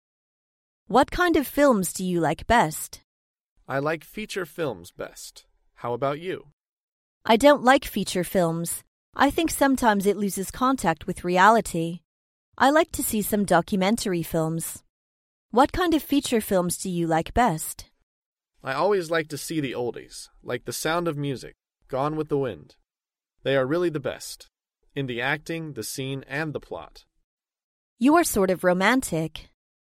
在线英语听力室高频英语口语对话 第451期:怀旧电影的听力文件下载,《高频英语口语对话》栏目包含了日常生活中经常使用的英语情景对话，是学习英语口语，能够帮助英语爱好者在听英语对话的过程中，积累英语口语习语知识，提高英语听说水平，并通过栏目中的中英文字幕和音频MP3文件，提高英语语感。